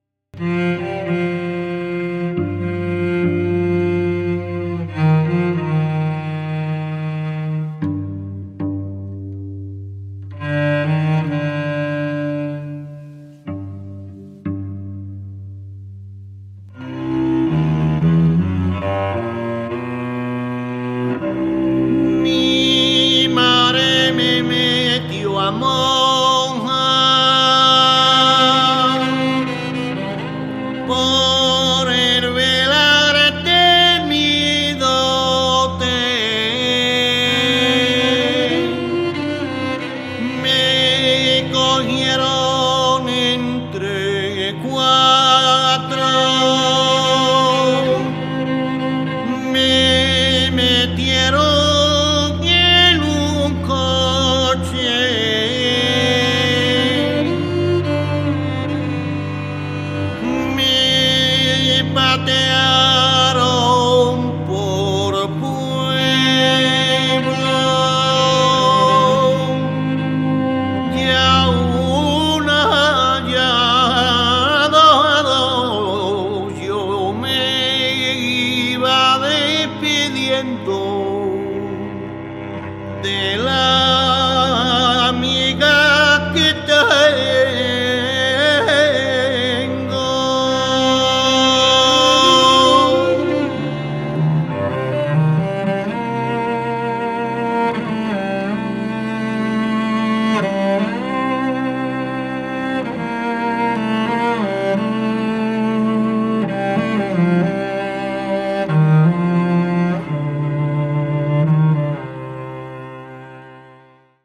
chant
violoncelle